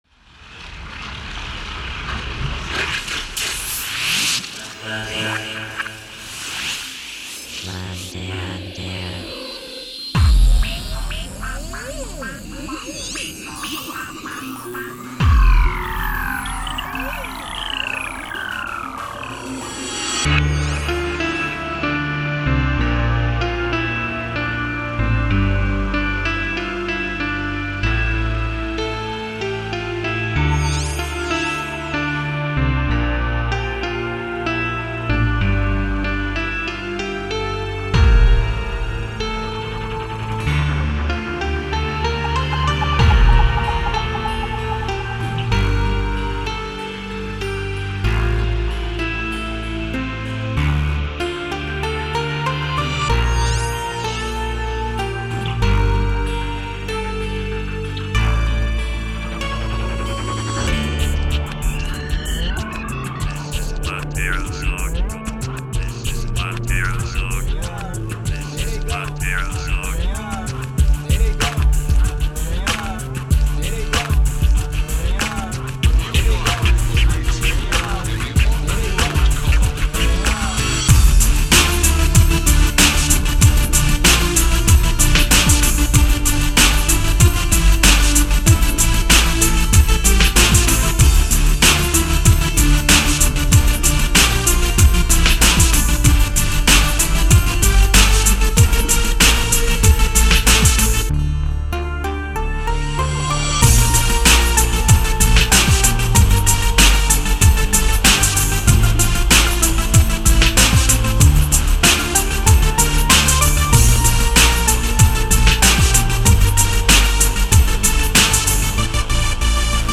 Написана спонтанно под соответствующее романтическое настроение